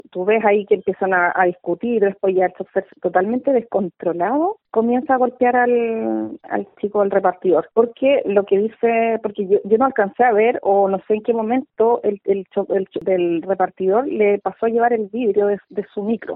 Así, al menos lo confirmó a Radio Bío Bío una conductora que estuve presente en el momento de la discusión vial, quien señaló que, al parecer, todo comenzó porque el delivery pasó a llevar el espejo del bus.